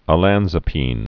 (ə-lănzə-pēn)